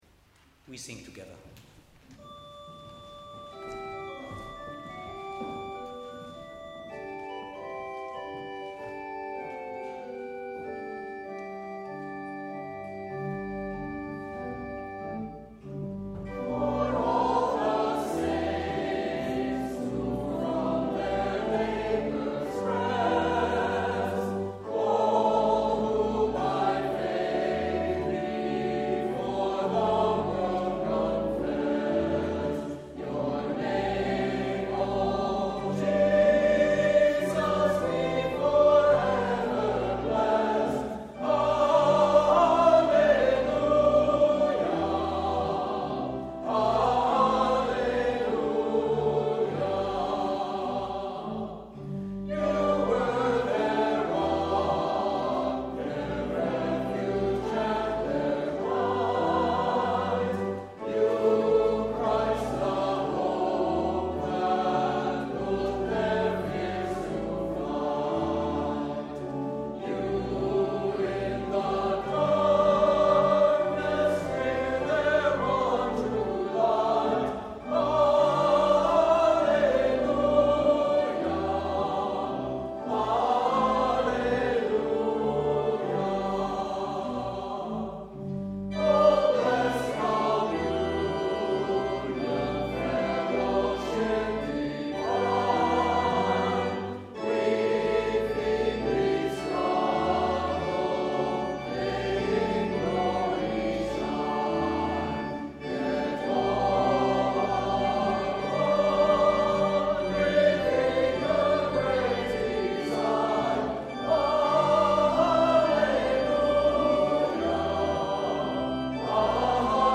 Our live-stream lost several minutes of sound last Sunday, so here is the “lost audio” — Hymn, Thanksgiving for Baptism, Hymn of praise, Prayer of the day, Readings.
ThanksgivingPrayer-of-the-DayReadings.mp3